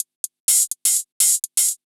UHH_ElectroHatB_125-02.wav